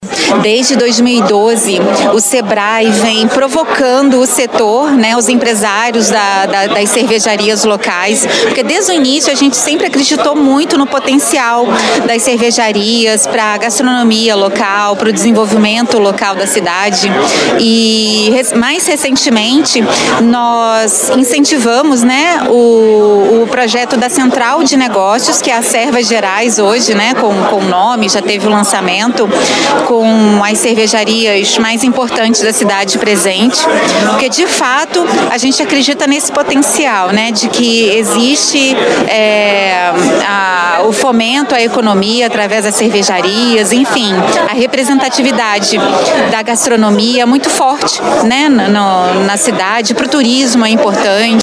Em um evento na noite de quarta-feira (9) no Seminário da Floresta, representantes dos setores envolvidos falaram sobre a importância da produção cervejeira na cidade, as novidades e a expectativa para este ano.